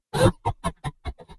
轻笑